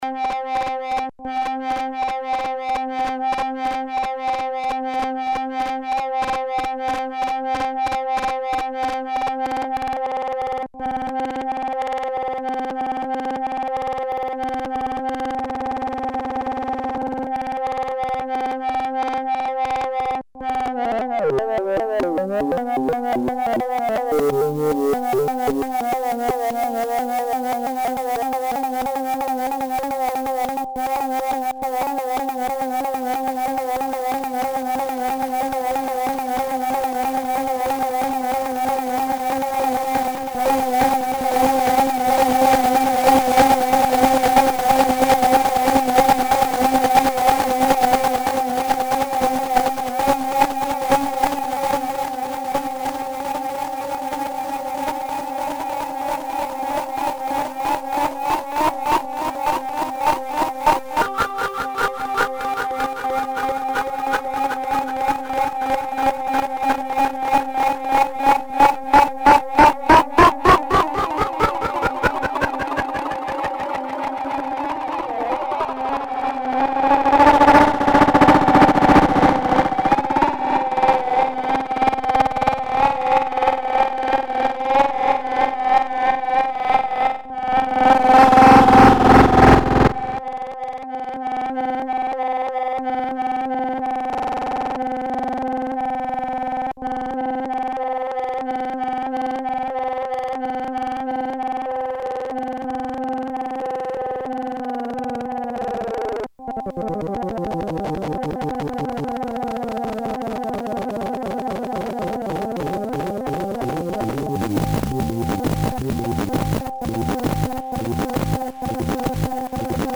SAMPLE organ tone
some of these are nearly 20mg - please watch your volumes there are some very loud sections in these. the samples are just me cutting crude loops of mine and other peoples work, computer - teaspoon - mixer - computer, nothing else. at a few points i plug in the attenuated audio output of a summed pair of sine VCO’s set to low frequencies into the CV input to simulate the audio outputs of a standard sound card, no other outside control is used. i try to cover as much range as i can without stopping on anything for too long so you will have to use your imagination to pause things a bit, there is a lot to cover. most tracks have the right side clean so you can compare the two. most of the time is spent with the SZ, FDBK, LNGTH and THRSH controls, the push button is only used a few times, tilt disabled.